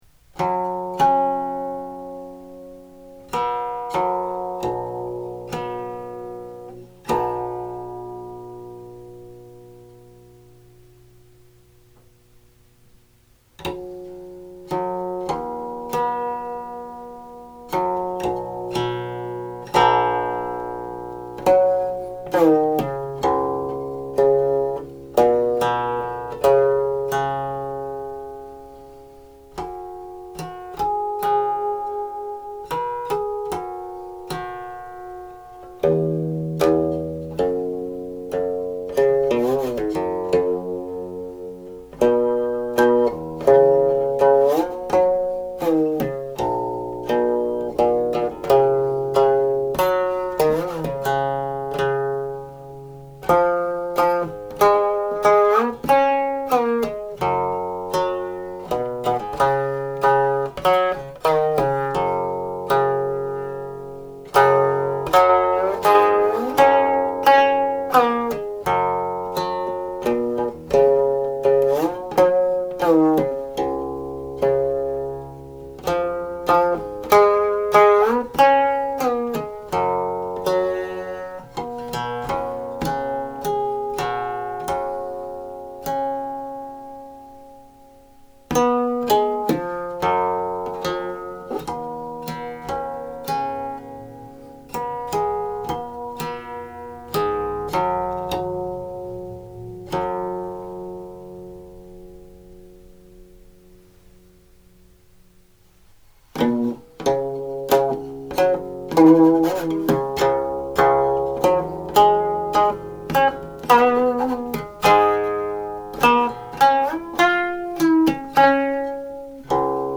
lyrics are paired to tablature largely by the traditional method that applies one character to each right hand stroke/pluck.
00.13   (On the recording the harmonic coda is also played here at the front16 )